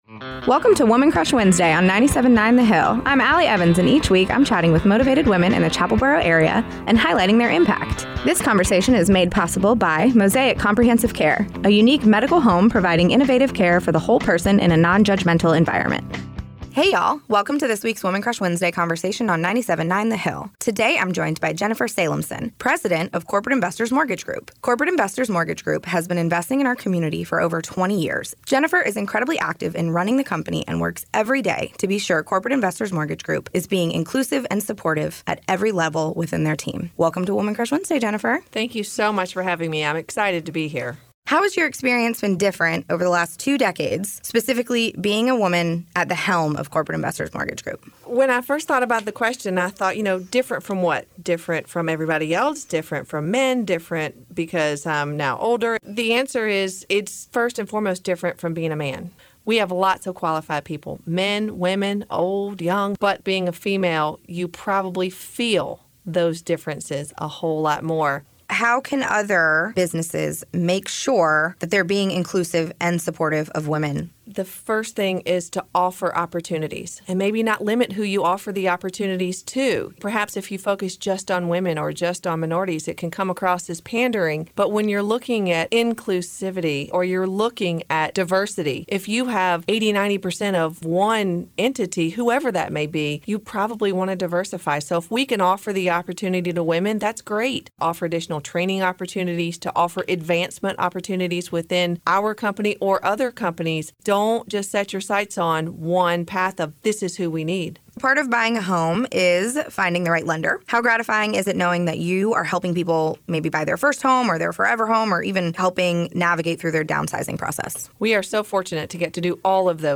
a three-minute weekly recurring segment